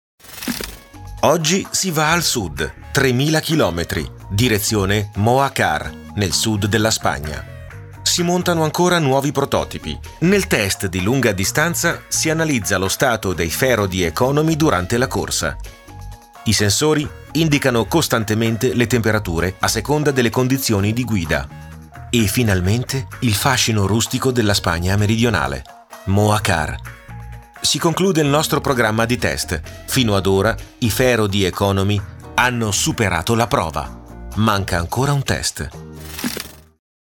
Sprechprobe: Industrie (Muttersprache):
Young and fresh voice and extremely flexible, perfect for e-learning, documentaries, web video, radio commercials, telephone responders, business, video games, etc.